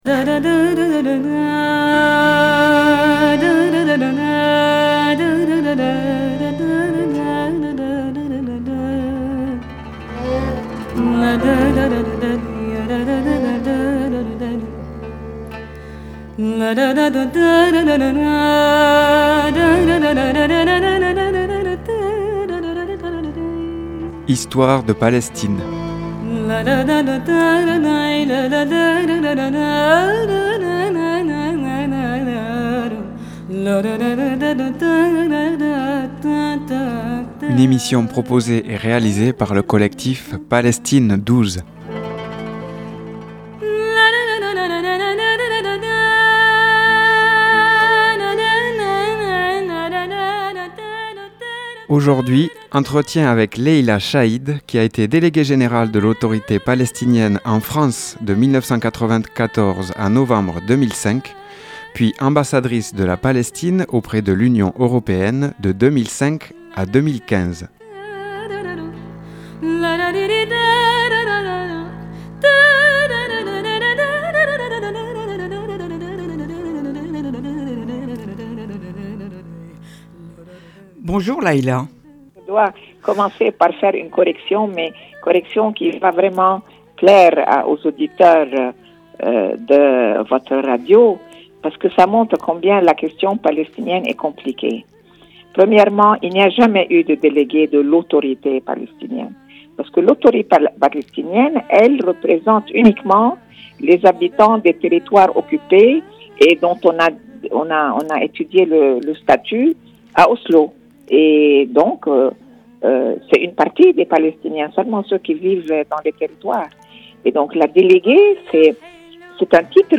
Histoire de Palestine – Entretien avec Leïla Shahid – 26 octobre 2023 - Radio Larzac